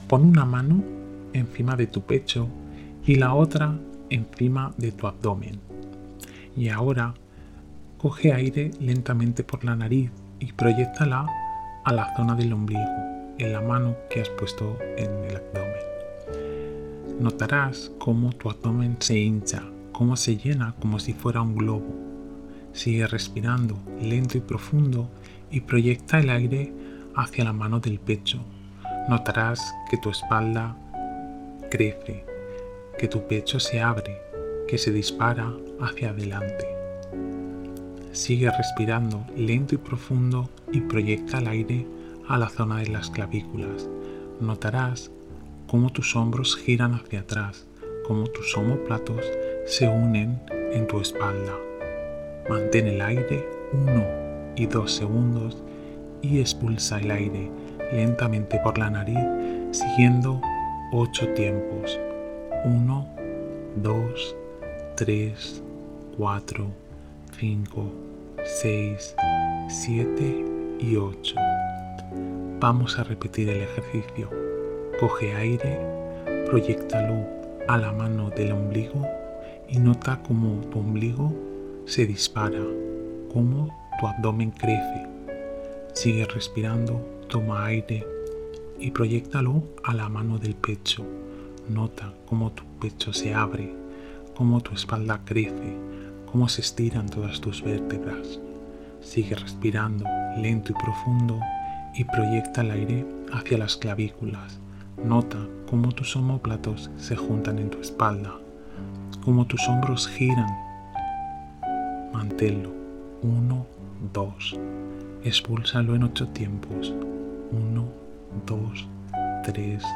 Te dejo aquí un pequeño audio tutorial para que puedas iniciar la práctica.